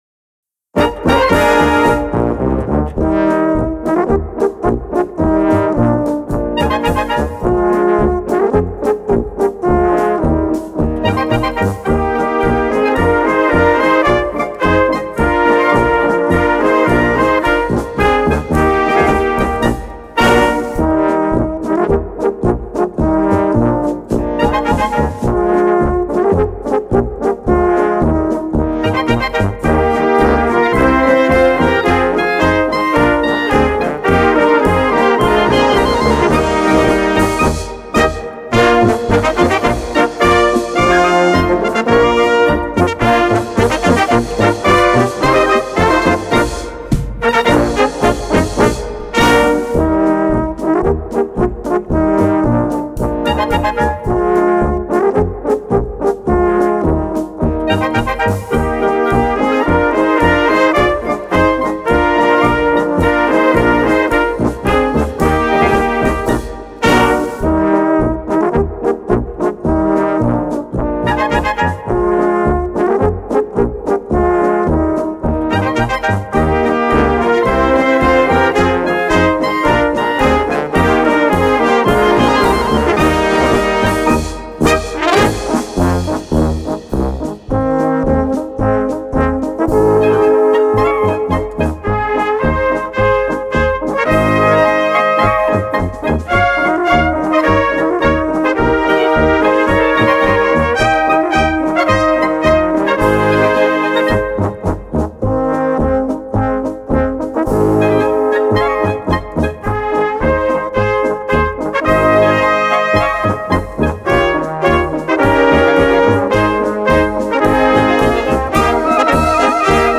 Kategorie Blasorchester/HaFaBra
Unterkategorie Polka
Besetzung Ha (Blasorchester)